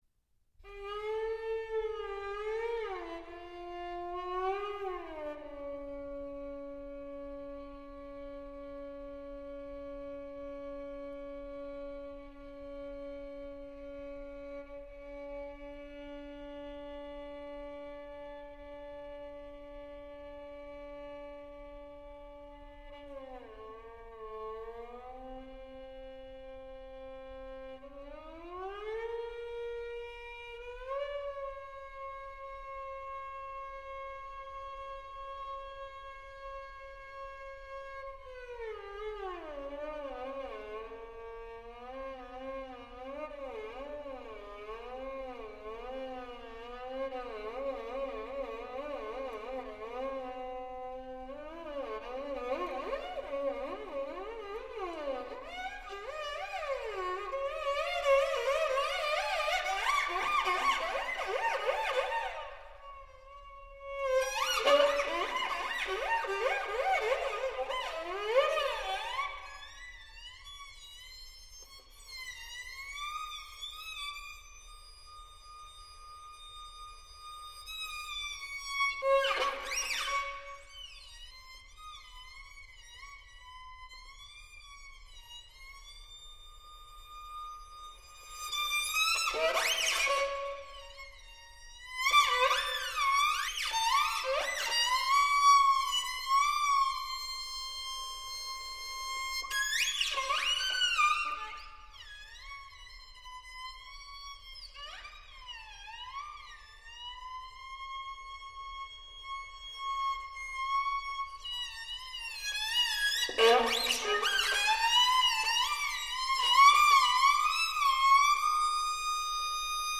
for solo violin